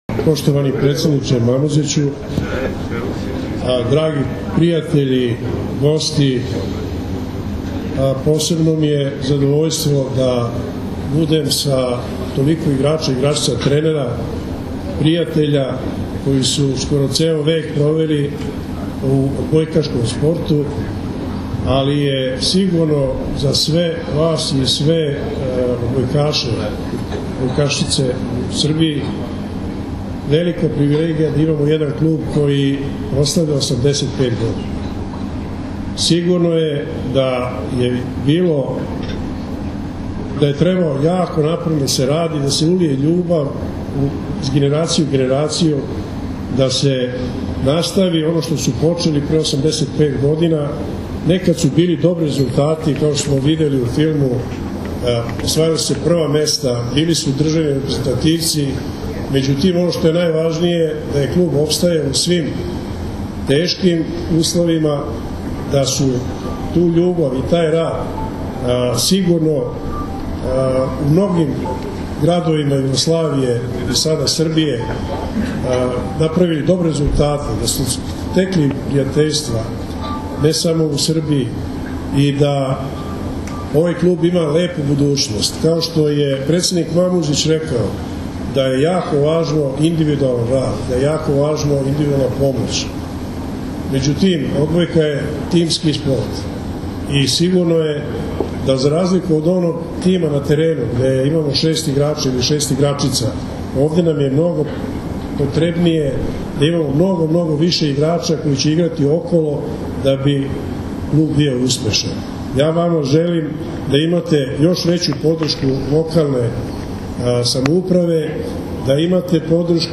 Odbojkaški klub “Jedinstvo” iz Stare Pazove proslavio je sinoć 85 godina postojanja na svečanosti održanoj u hotelu “Vojvodina” u Staroj Pazovi.
OBRAĆANJE